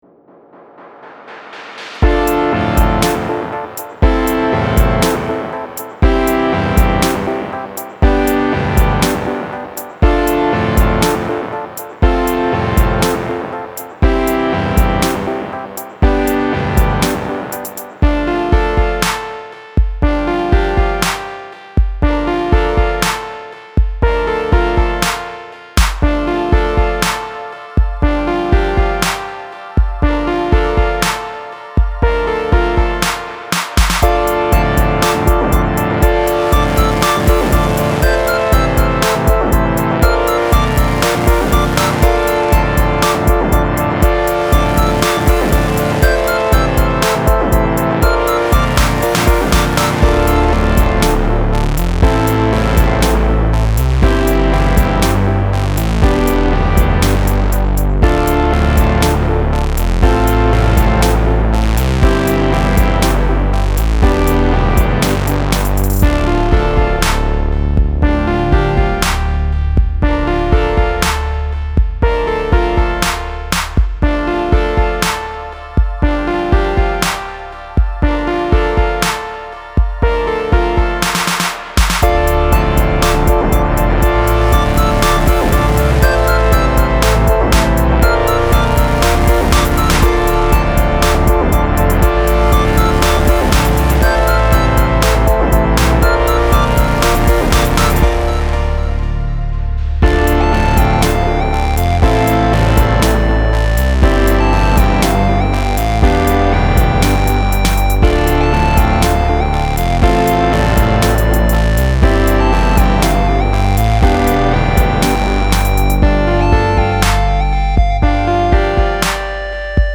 Style Style EDM/Electronic
Mood Mood Cool, Intense
Featured Featured Bass, Drums, Synth
BPM BPM 120